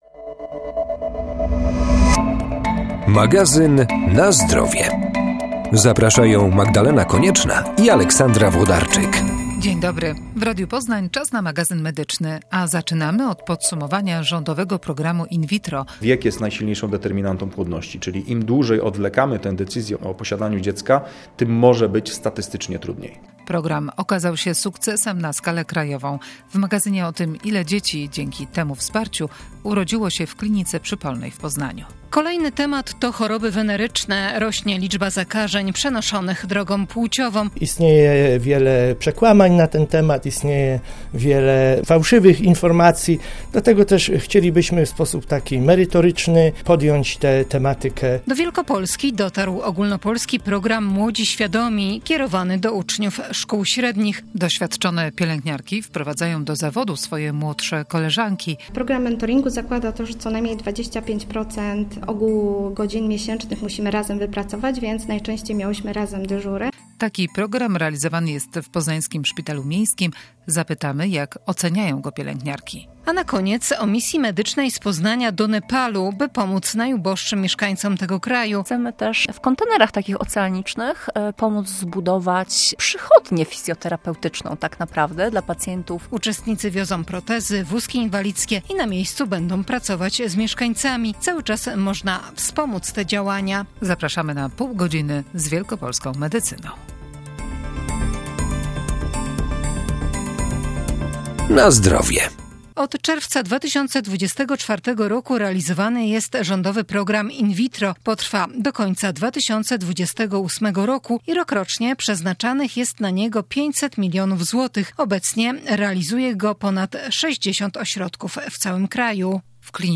Będzie też o kampanii edukacyjnej dla uczniów szkół średnich na temat chorób przenoszonych drogą płciową - statystycznie jest ich coraz więcej. W rpogramie także rozmowa z pielęgniarkami ze Szpitala im. Strusia, uczestniczącymi w finansowanym z KPO projekcie mentoringu. A na koniec o misji medycznej z Poznania do Nepalu - około 40 osób bierze udział w Katmandu Therapy Camp.